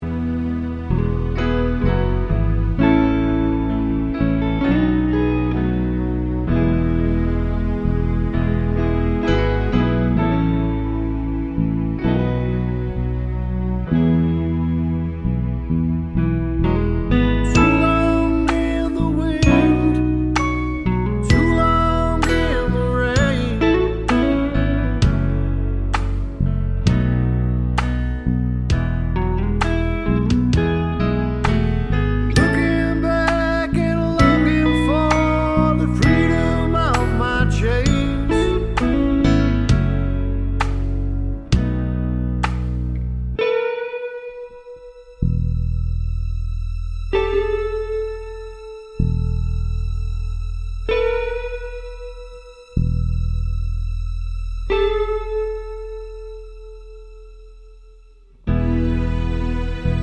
no Backing Vocals Soul / Motown 3:04 Buy £1.50